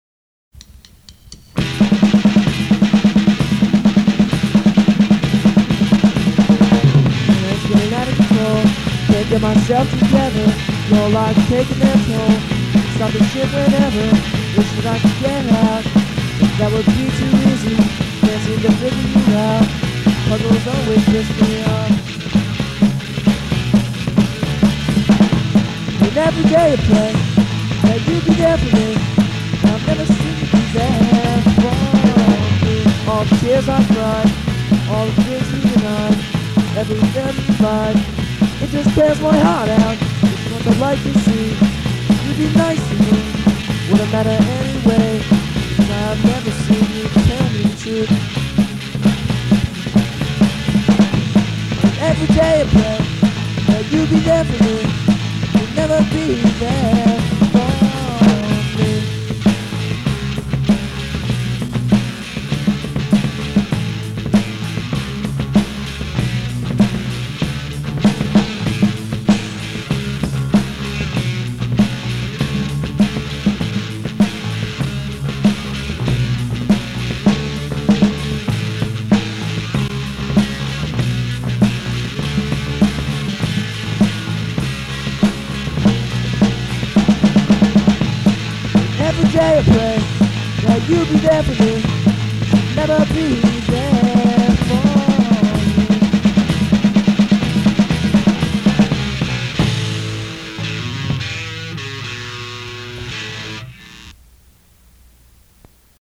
This line up recorded 8 new songs, again on 4-track